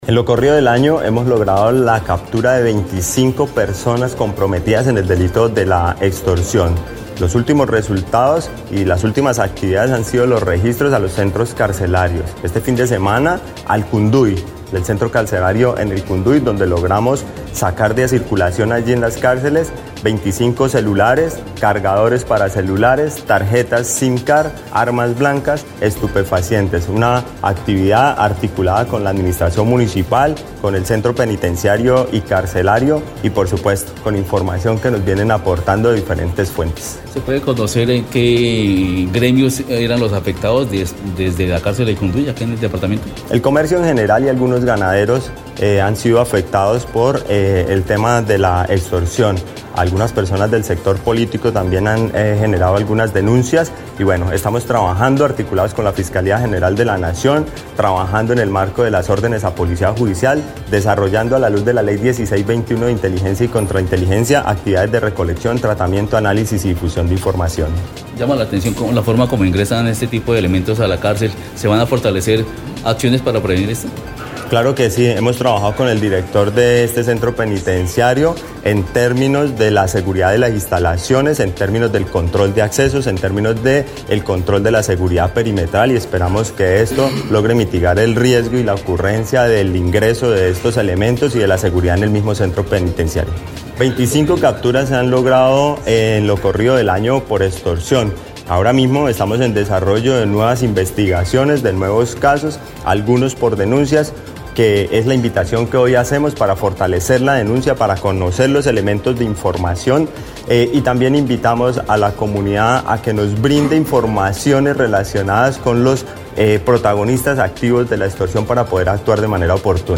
El comandante de la Policía Caquetá, coronel, César Pinzón, explicó que lo anterior hace parte de la lucha frontal en contra del delito de la extorsión, donde las víctimas más frecuentes son comerciantes y ganaderos.